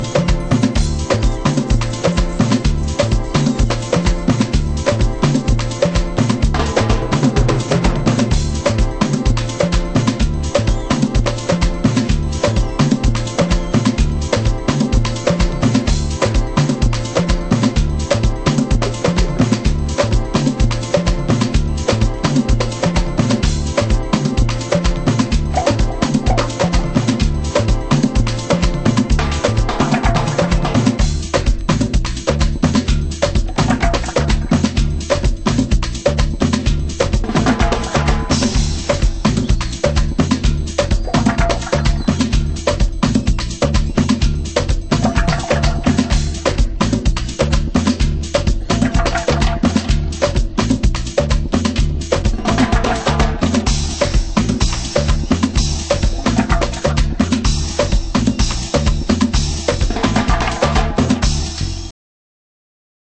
盤質：A面に傷によるノイズが出る箇所有/B面に傷多し/どちらも試聴箇所になっています/PROMO